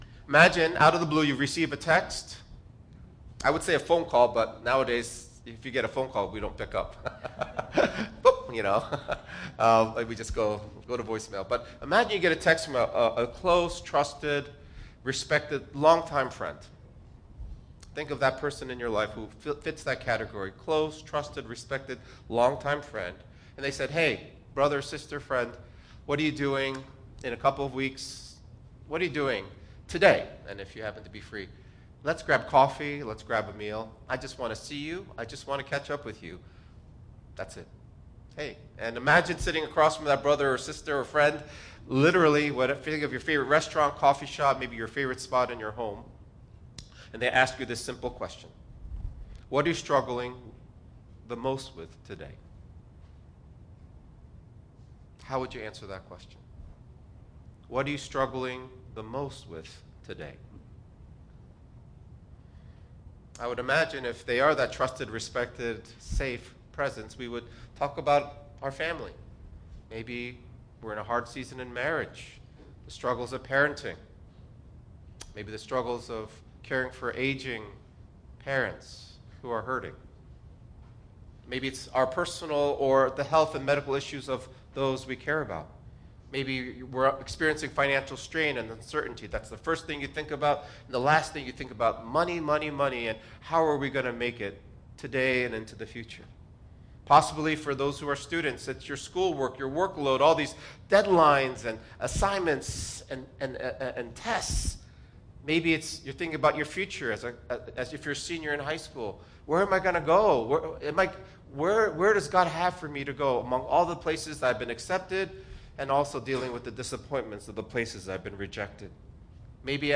260222-Sermon.mp3